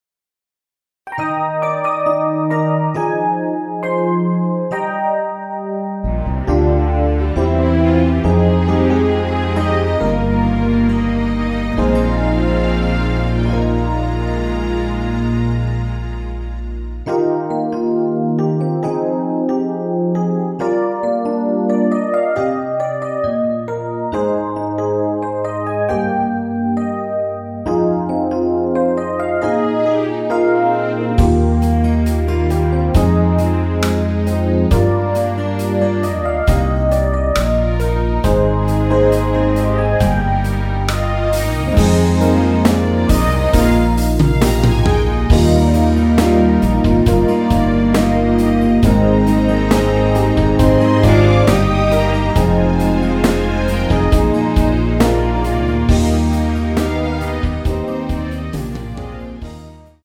Eb
앨범 | O.S.T
앞부분30초, 뒷부분30초씩 편집해서 올려 드리고 있습니다.